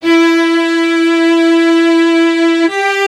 Track 11 - Cello 01.wav